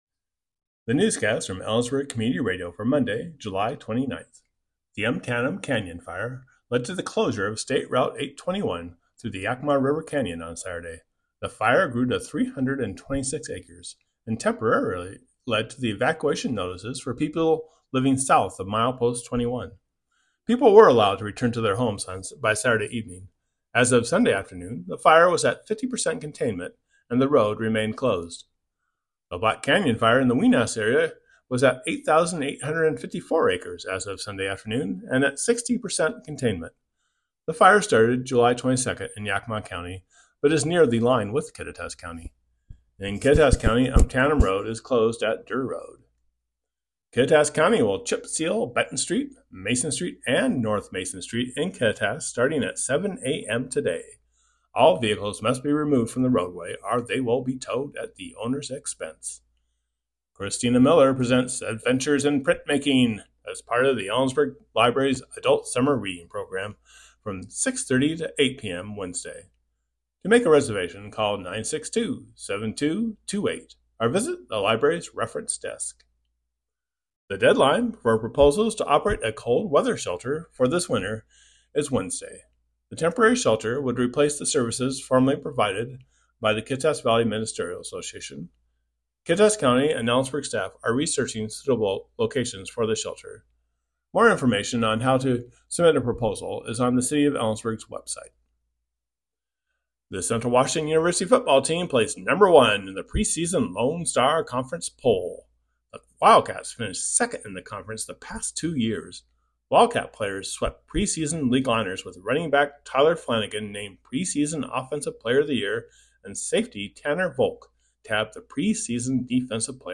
Click here to listen to today's newscast.